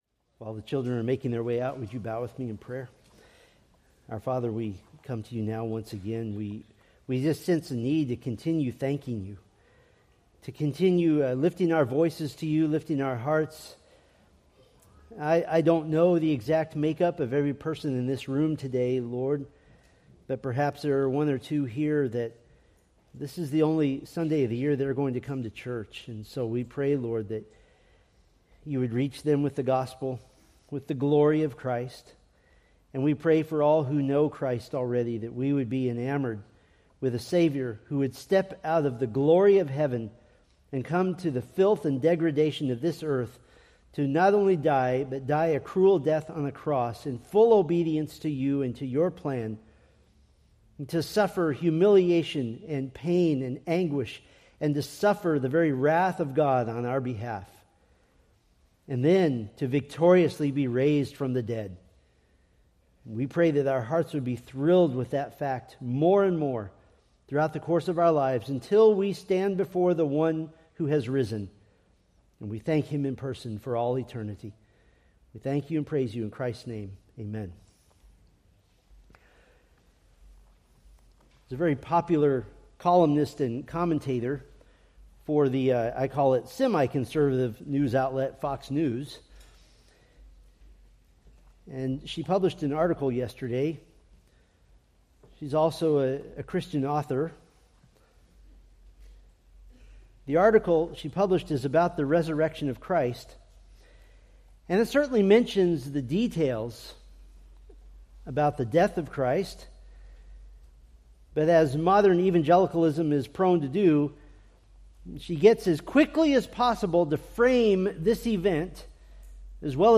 Sermon Details